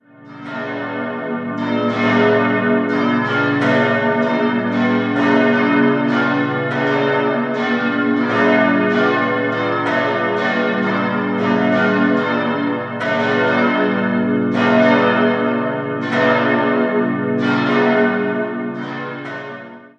Verminderter Dreiklang: gis°-h°-d' Alle Glocken wurden 1913 vom Bochumer Verein für Gussstahlfabrikation gegossen.